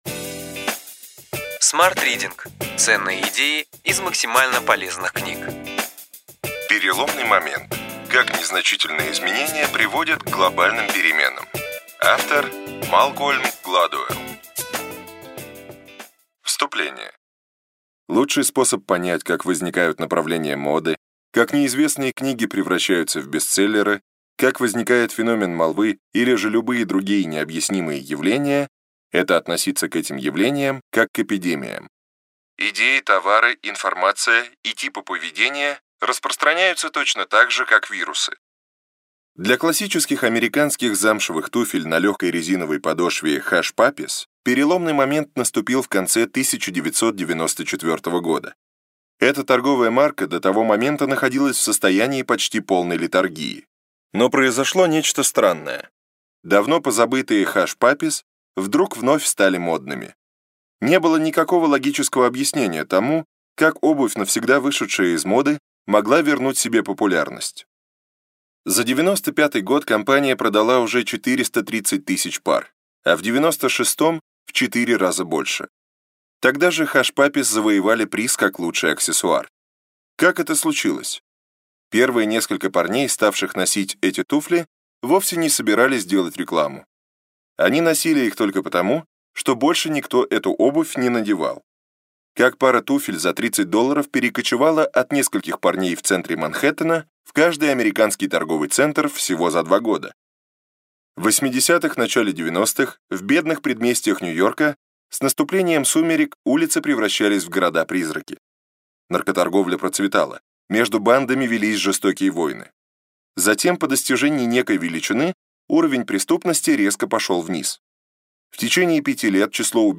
Аудиокнига Ключевые идеи книги: Переломный момент. Как незначительные изменения приводят к глобальным переменам.